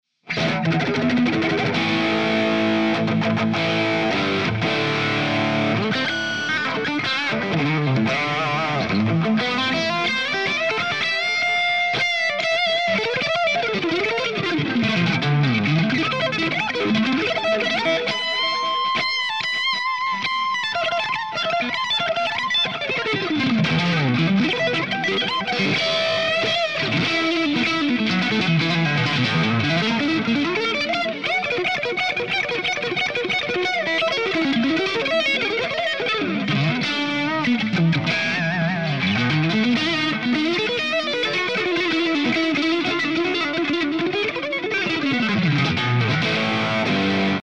Improv
RAW AUDIO CLIPS ONLY, NO POST-PROCESSING EFFECTS